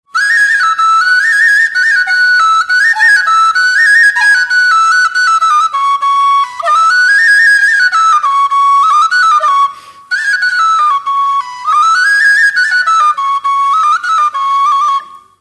Dallampélda: Hangszeres felvétel
Hangszeres felvétel Moldva és Bukovina - Moldva - Klézse Előadó
furulya